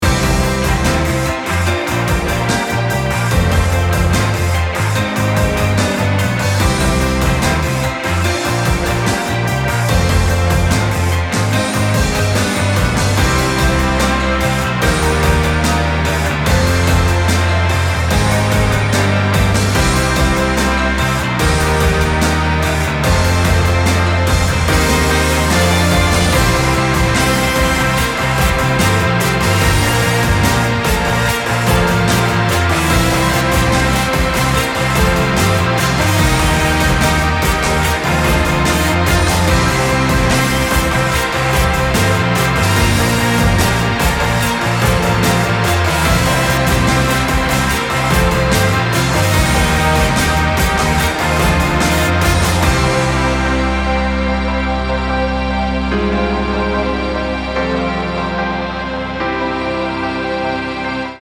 And the overall RMS stayed basically the same, so it shouldn’t sound “weak”, just less prickly.